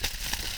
lightning3.wav